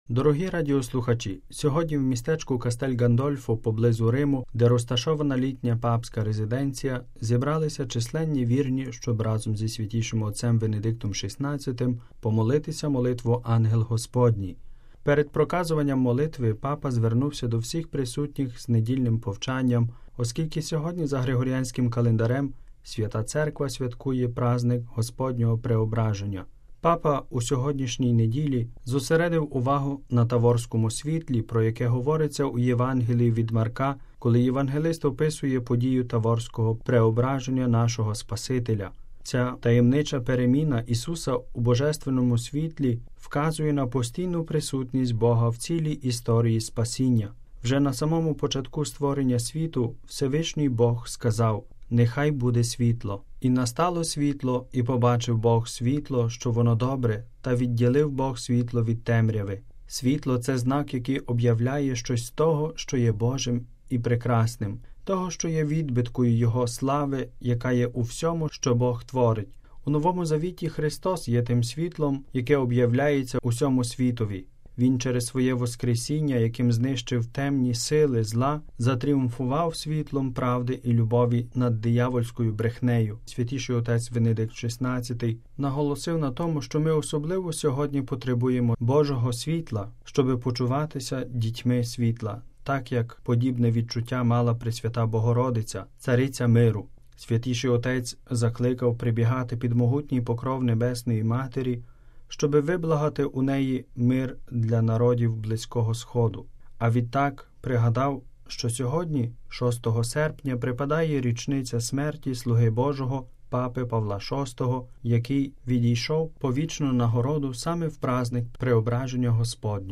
Сьогодні в містечку Кастель Ґандольфо поблизу Риму, де розташована літня папська резиденція, зібралися численні вірні, щоб разом зі Святішим Отцем Венедиктом XVI помолитися молитву «Ангел Господній».